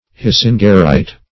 Search Result for " hisingerite" : The Collaborative International Dictionary of English v.0.48: Hisingerite \His"ing*er*ite\, n. [Named after W. Hisinger, a Swedish mineralogist.]